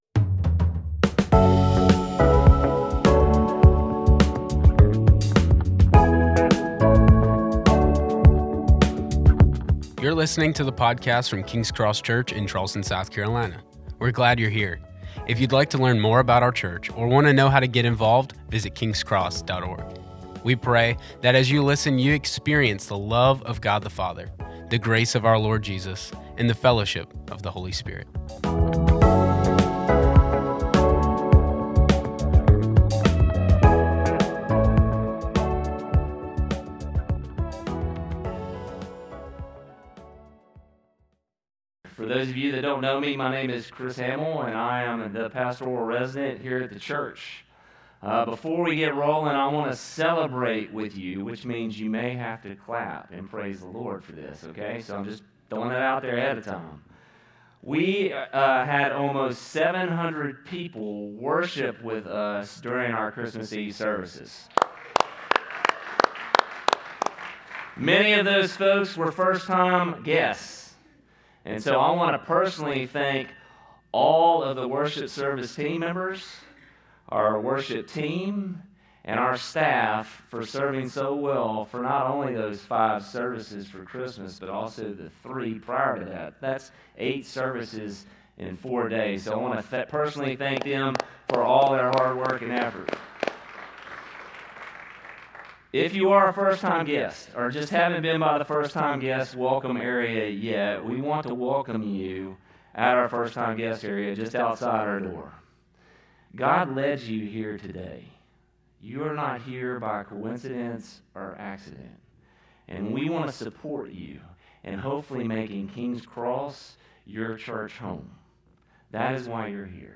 A message from the series "Together."